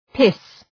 Προφορά
{pıs}